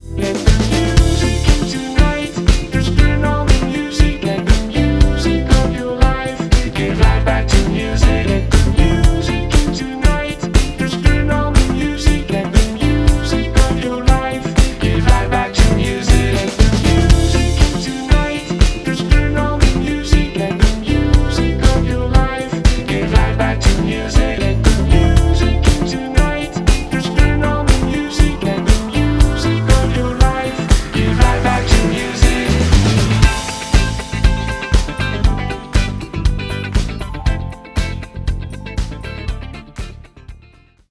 • Качество: 256, Stereo
мелодичные
Synth Pop
Electronic
синти-поп
Техно